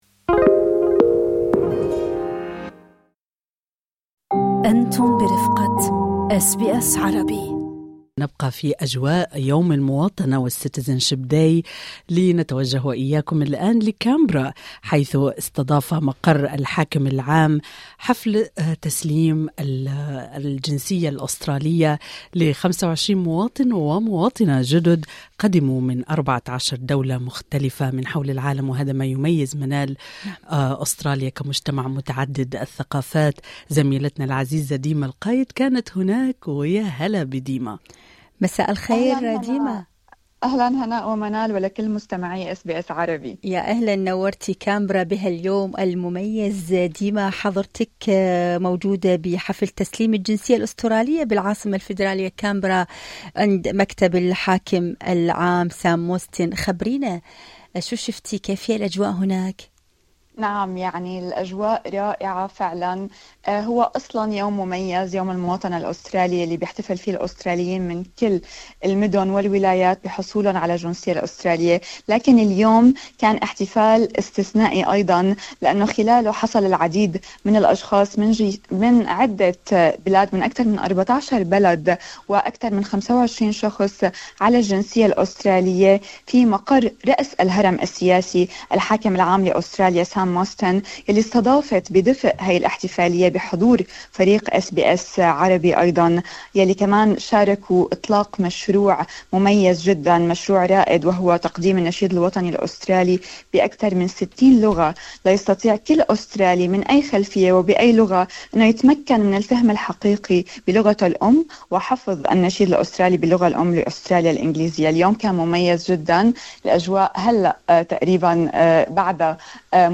اقرأ المزيد يوم المواطنة الأسترالية: حين تتحول الهجرة إلى انتماء ووطن جديد وحضرت SBS Arabic حفل تسليم الجنسية الاسترالية الذي استضافته الحاكمة العامة الاسترالية Sam Mostyn.